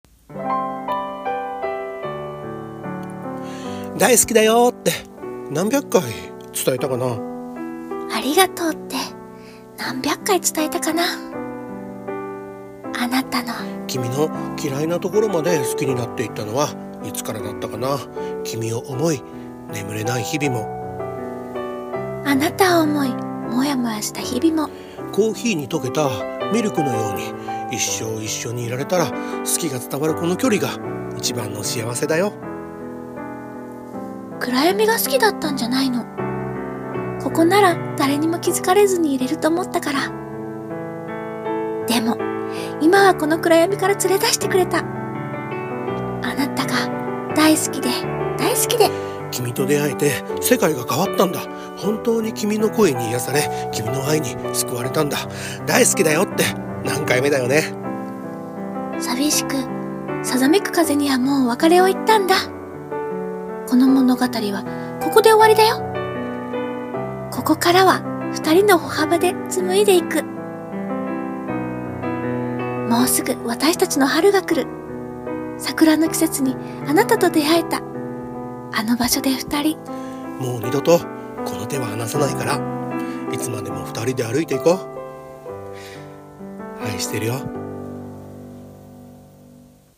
〖二人声劇〗初恋